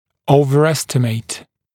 [ˌəuvər’estɪmeɪt][ˌоувэр’эстимэйт]переоценивать